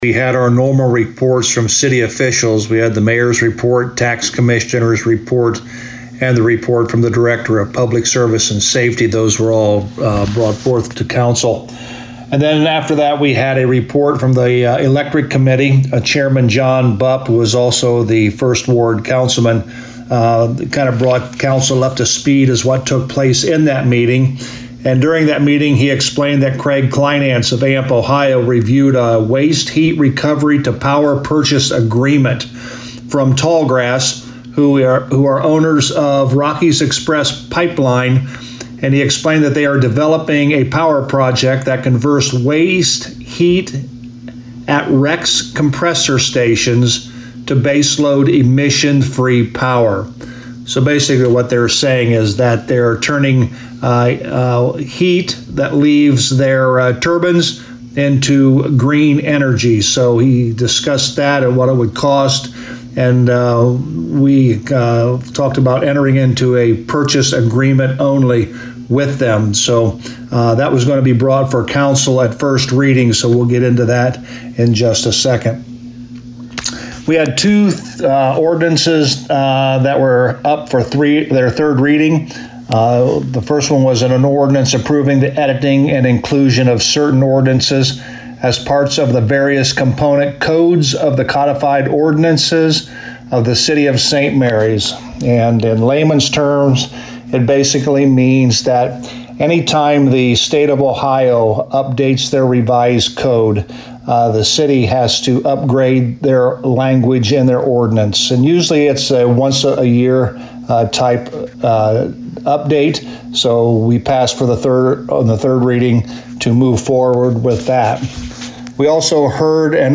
To hear a summary with St Marys Mayor Joe Hurlburt: